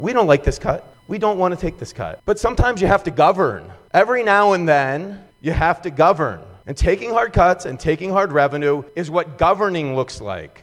On Tuesday, the Maryland House of Delegates went over almost 200 budget amendments, including a $25 million dollar cut in Program Open Space funds from rural legacy programs, land preservation and state open space funding. After debate on the cuts, Democratic Delegate Ben Barnes said such actions are needed now…